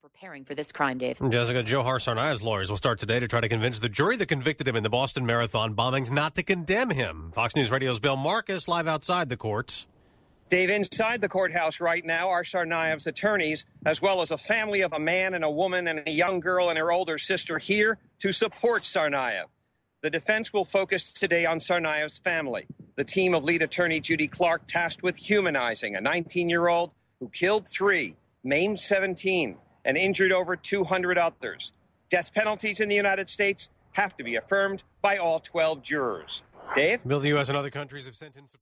(BOSTON) APRIL 27 – FOX NEWS RADIO – 9AM LIVE –